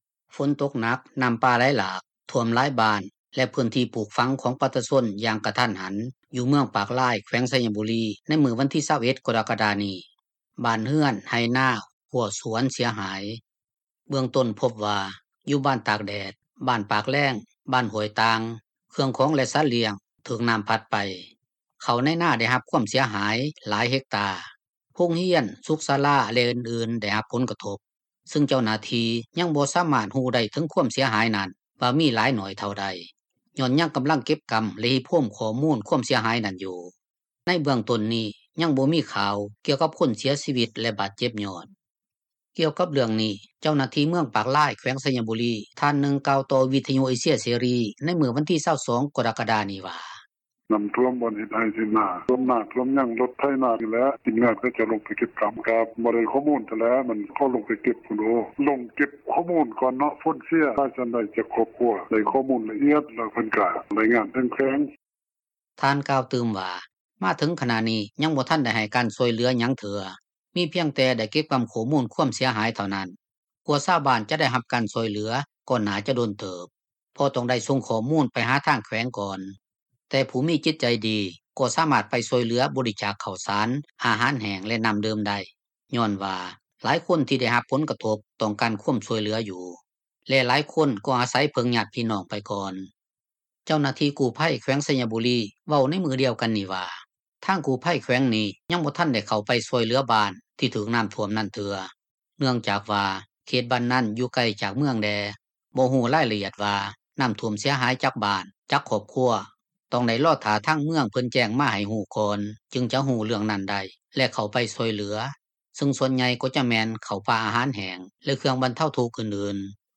ນັກຂ່າວ ພົລເມືອງ
ກ່ຽວກັບເຣື່ອງນີ້ ເຈົ້າໜ້າທີ່ເມືອງປາກລາຍ ແຂວງໄຊຍະບູຣີ ທ່ານນຶ່ງກ່າວຕໍ່ວິທຍຸເອເຊັຽເສຣີໃນມື້ວັນທີ 22 ກໍຣະກະດານີ້ວ່າ: